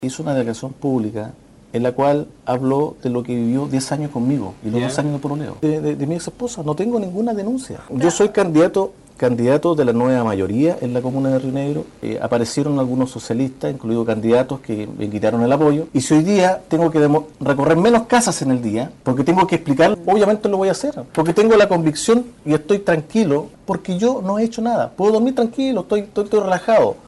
Fue en el programa Actualidad  Política, emitido por Radio Sago e Inet Televisión, donde el candidato independiente a alcalde en la comuna de Río Negro por la Nueva Mayoría, Jaime Ramos, se refirió a la polémica que lo involucra por el caso de violencia de género en contra de quien fuera su ex pareja.